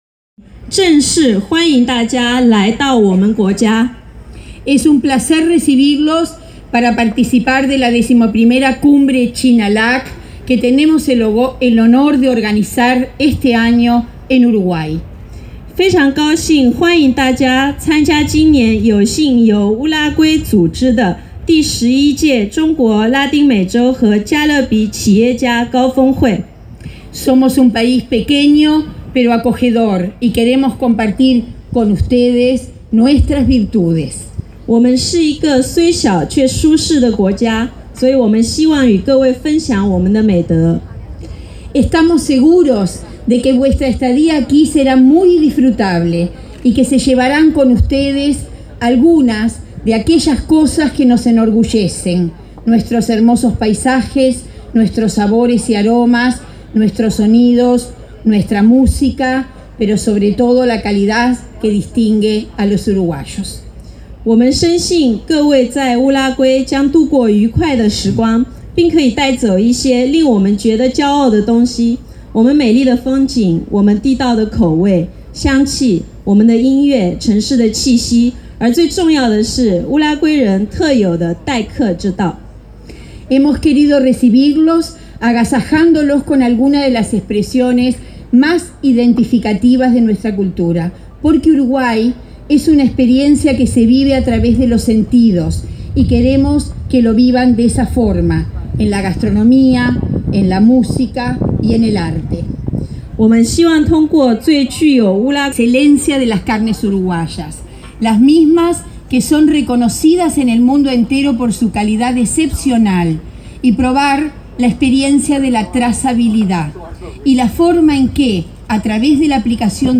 La Ministra de Turismo Liliam Kechichiam brindó la bienvenida al país en la previa de China LAC, a cerca de 600 empresarios. Destacó las bondades de Uruguay, sus paisajes, productos de calidad y su gente.
Escuchar las palabras de la
ministra de Turismo. mp3